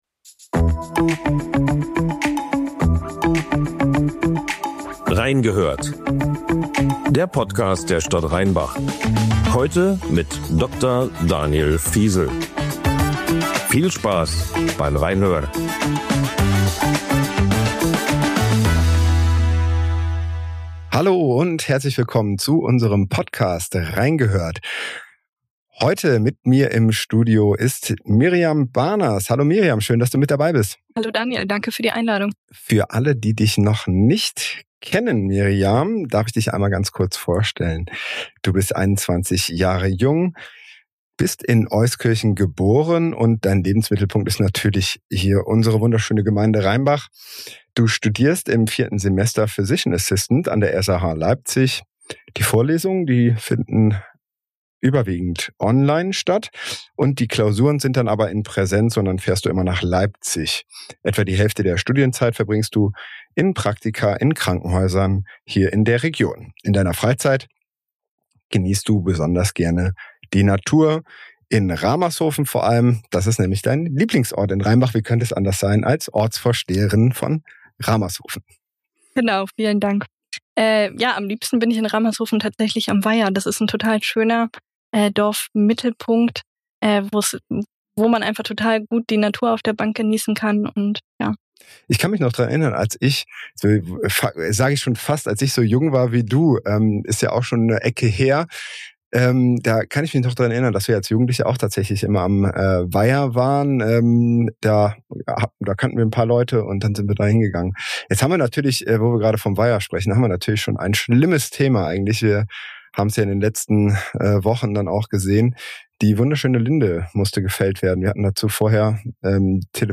Beschreibung vor 2 Wochen In Folge 3 von „Rhein gehört“ spricht Bürgermeister Dr. Daniel Phiesel mit Miriam Bahners, Ortsvorsteherin von Ramershofen und Mitglied der FDP.